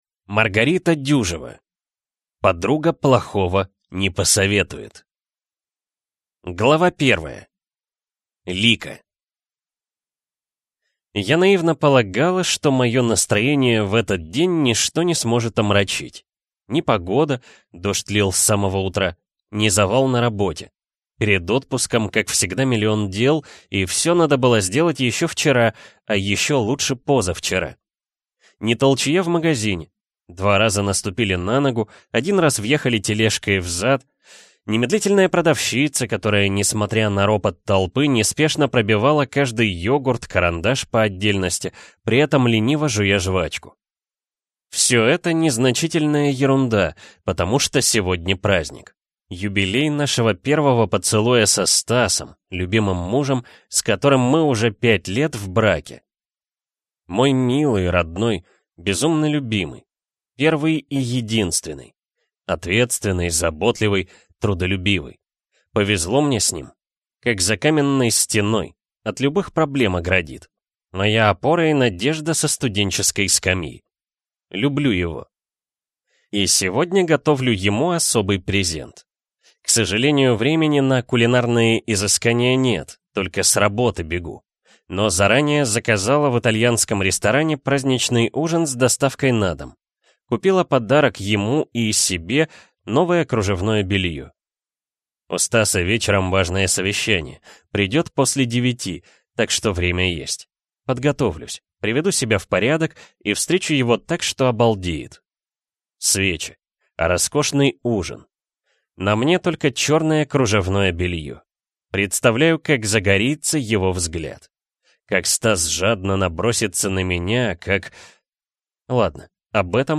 Аудиокнига Подруга плохого не посоветует | Библиотека аудиокниг